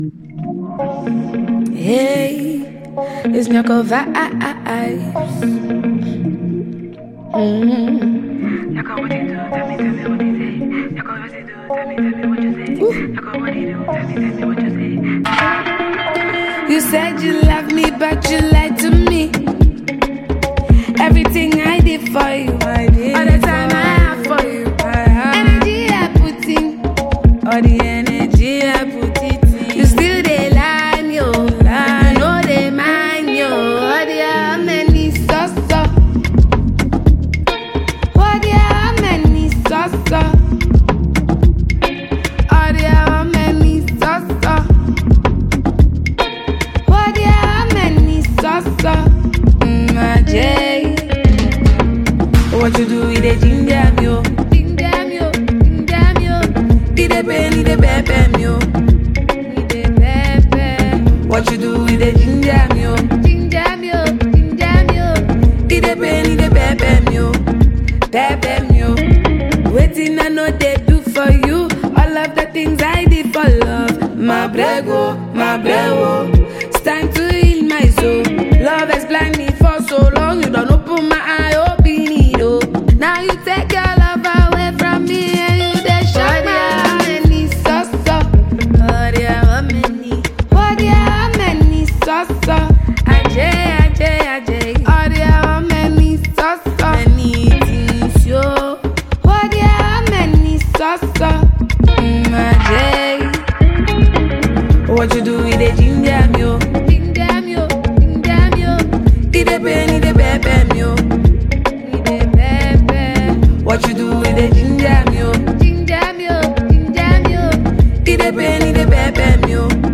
Ghanaian female rapper